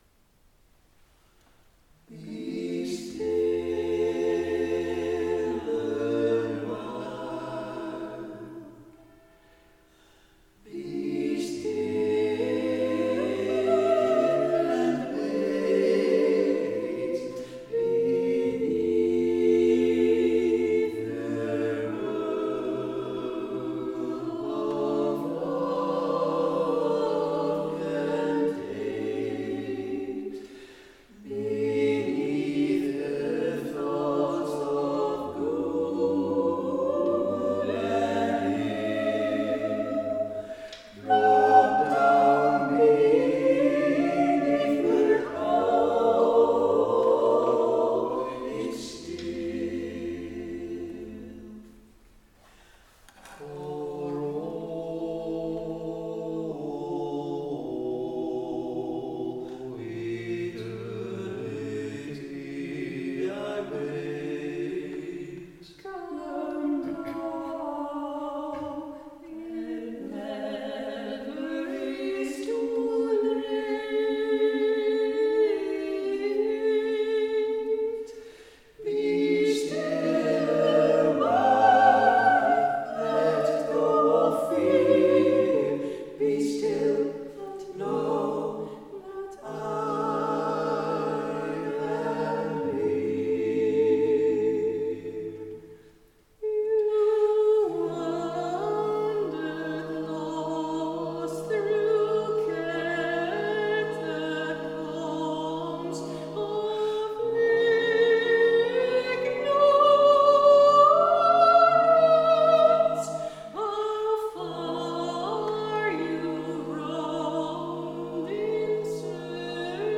Soprano
Alto
Tenor